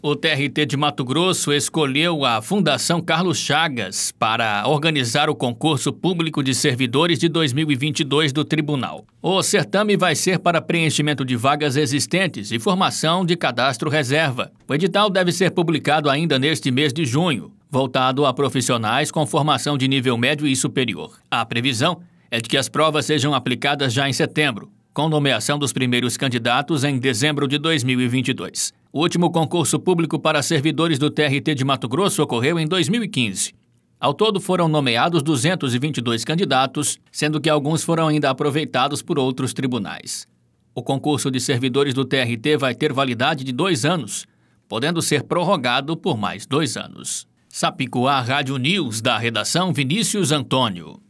Boletins de MT 20 jun, 2022